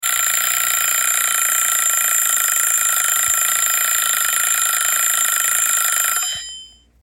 Alarm Clock Ring - Botão de Efeito Sonoro